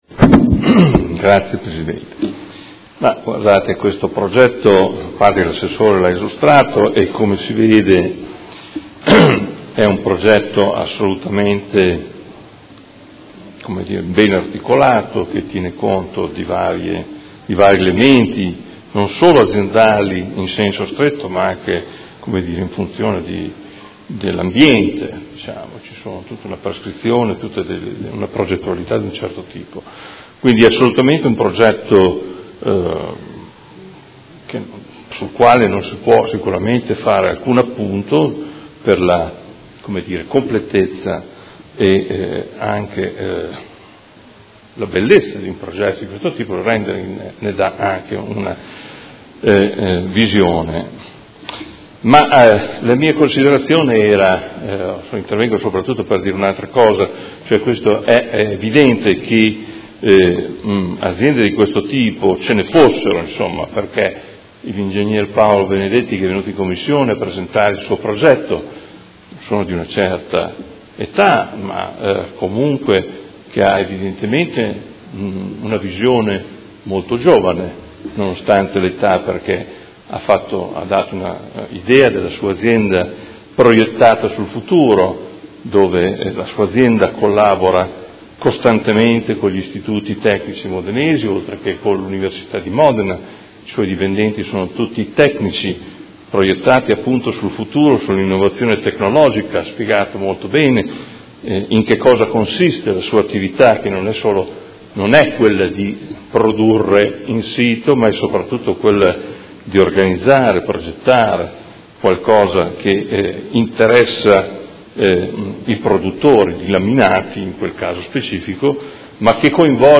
Seduta del 04/06/2018.